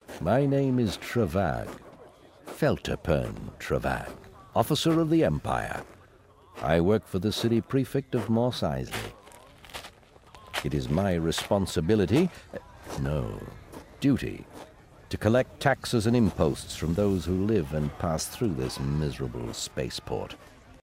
In its audio dramatization, Feltipern Trevagg is voiced by Alan Oppenheimer.